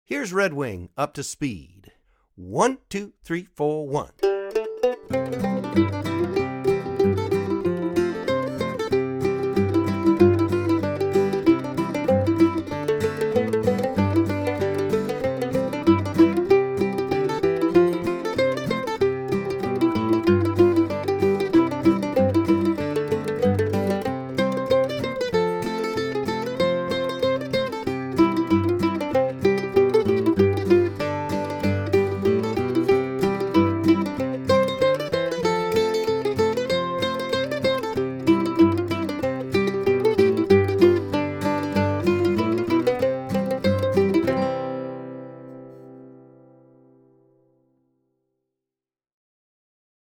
DIGITAL SHEET MUSIC - MANDOLIN SOLO
(both slow and regular speed)